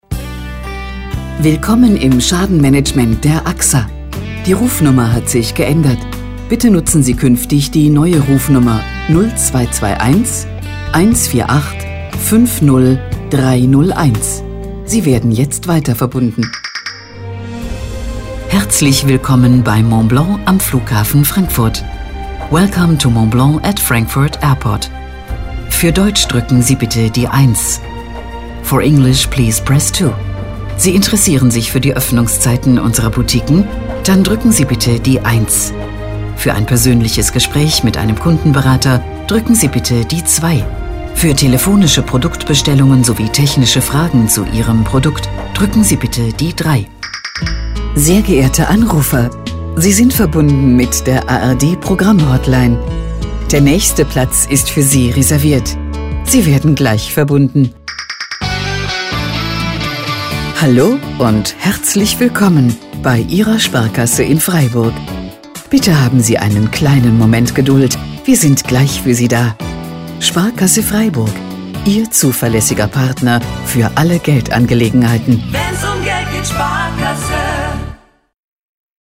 Anrufbeantworter-Ansage Sprecher - Synchronsprecher
🟢 Standard Sprecher